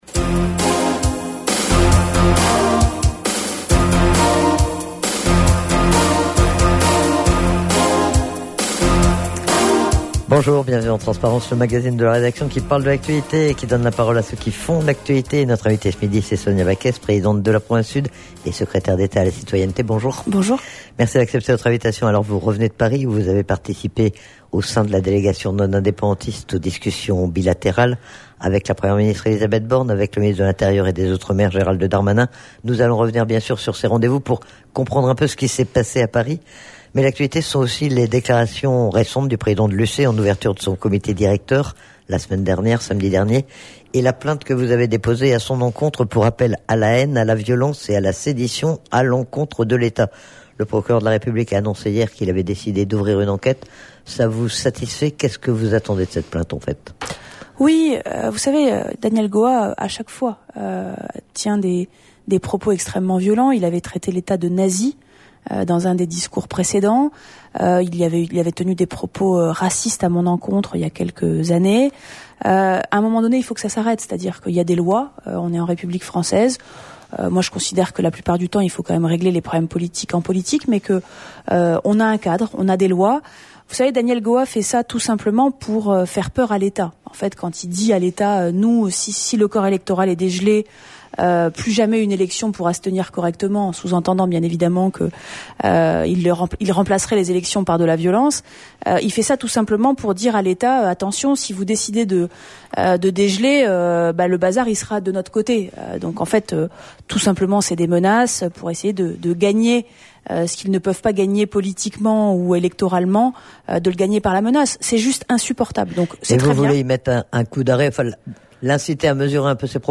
Sonia Backès est interrogée sur les discussions bilatérales qui se sont déroulées, il y a quelques jours, à Paris et sur la suite du processus institutionnel.